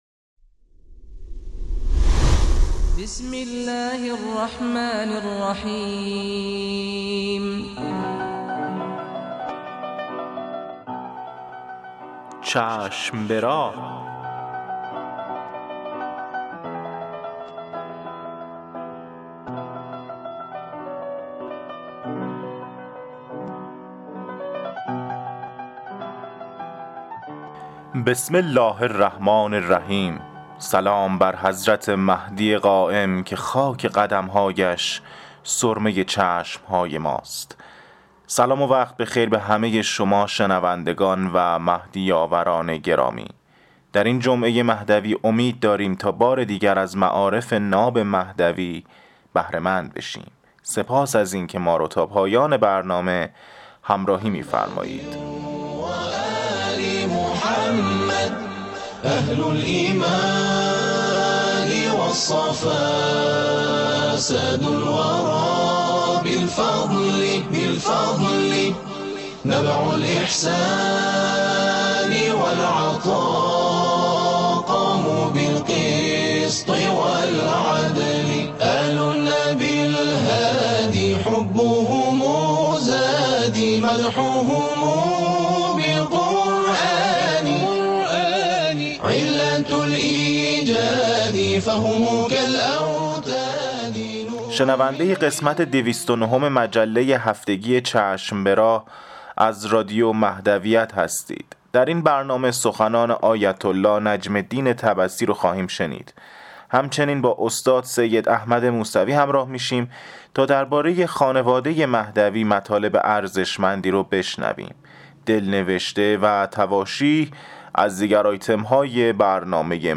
قسمت دویست و نهم مجله رادیویی چشم به راه که با همت روابط عمومی بنیاد فرهنگی حضرت مهدی موعود(عج) تهیه و تولید شده است، منتشر شد.